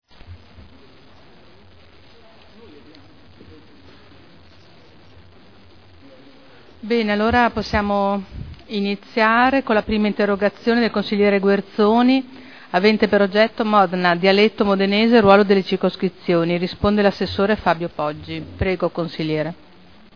Il Presidenta Caterina Liotti apre la seduta con le interrogazioni.